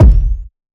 Kick [Timbo].wav